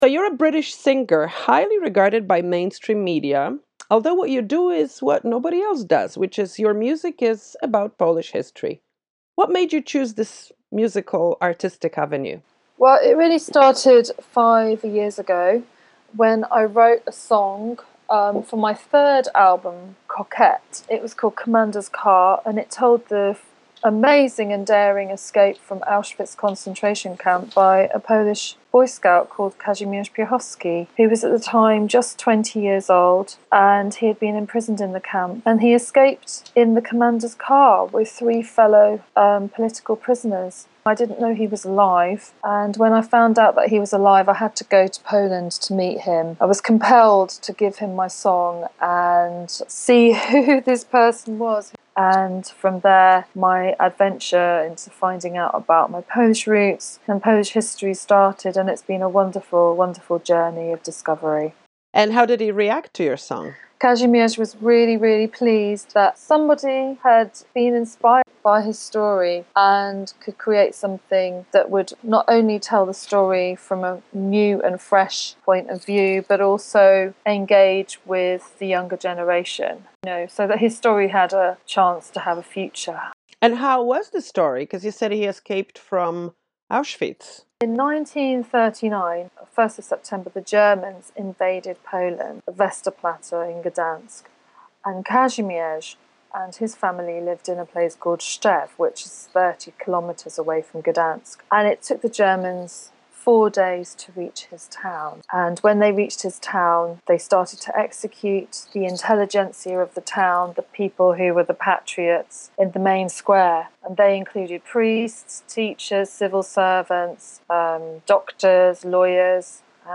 Here is the longer version of our conversation: